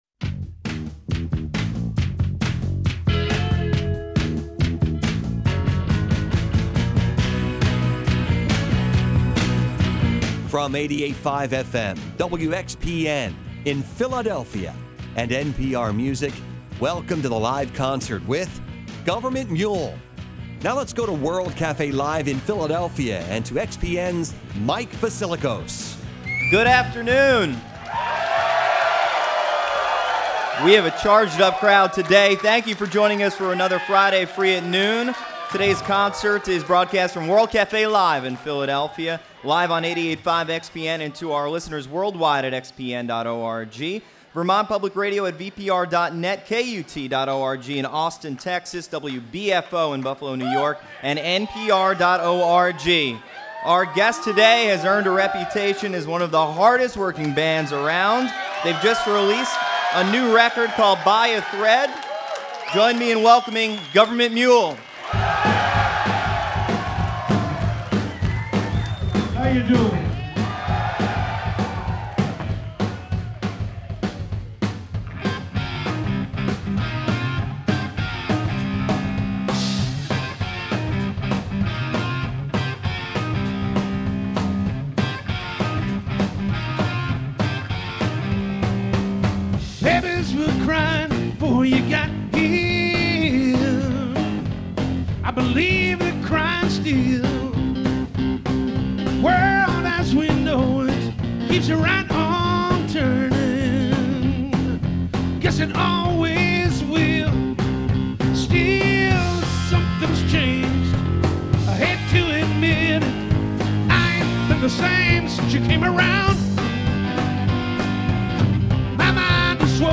Its songs stomp and wail with unmistakable energy and heft.
perform live in concert